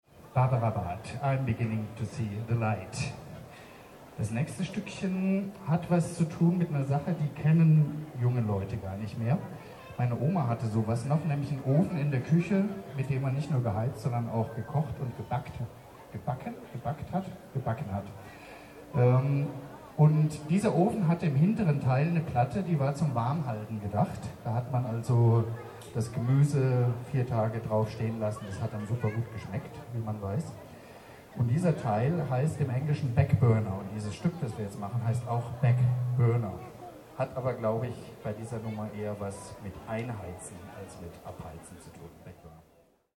17 - Ansage.mp3